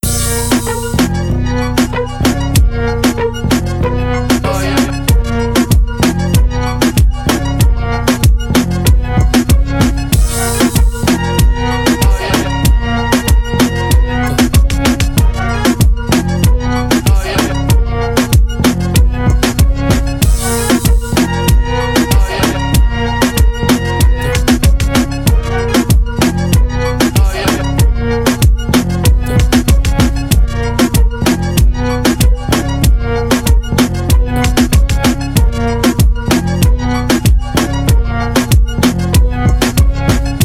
Club Beats
Latin Trap Type Beat